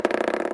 Diceroll.88628dc3.mp3